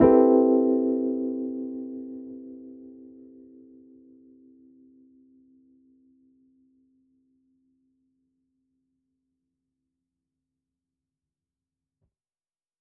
JK_ElPiano3_Chord-Cmaj13.wav